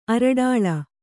♪ araḍāḷa